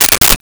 Stapler 03
Stapler 03.wav